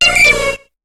Cri de Lippouti dans Pokémon HOME.